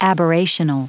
Prononciation du mot aberrational en anglais (fichier audio)
Prononciation du mot : aberrational